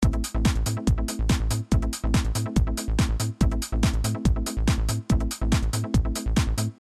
Techno Drum&Bass